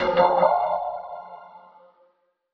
FX (PLANET) (1).wav